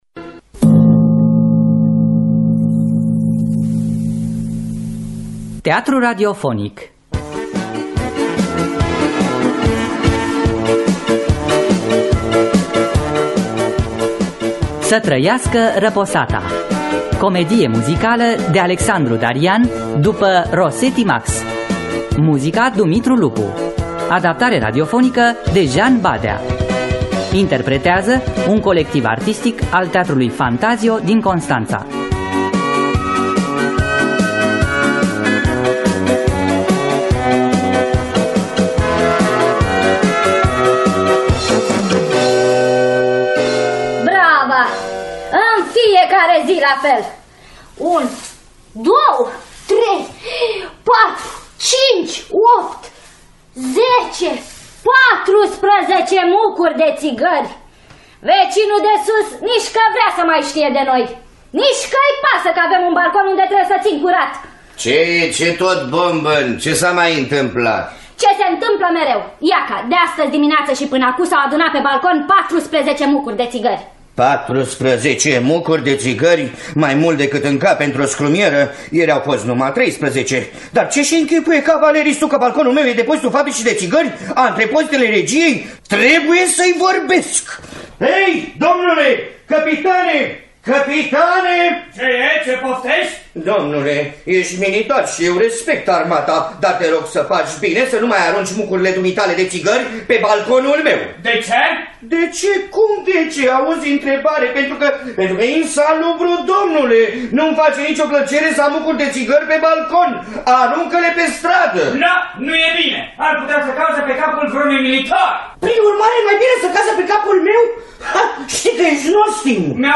Să trăiască răposata! de Max (Dimitrie Radu) Rosetti – Teatru Radiofonic Online
Adaptarea radiofonică